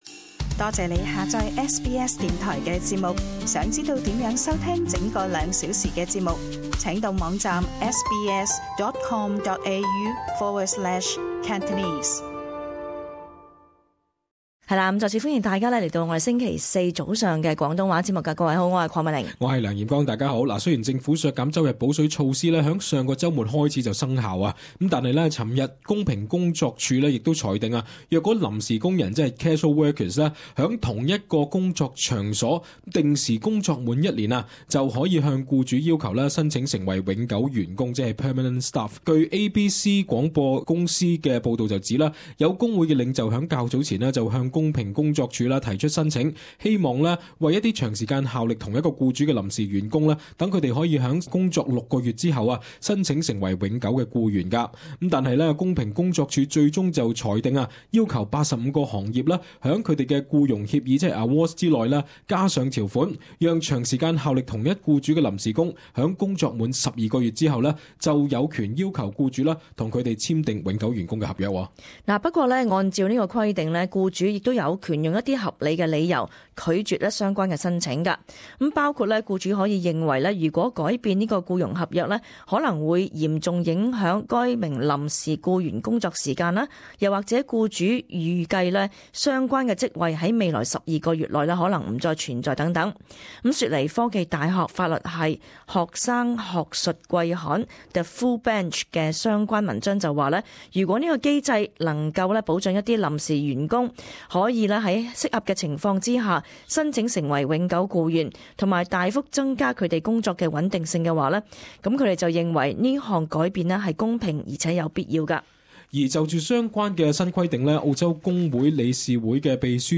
【時事報導】臨時工就職一年可申請永久合約